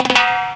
DRUMFILL01-R.wav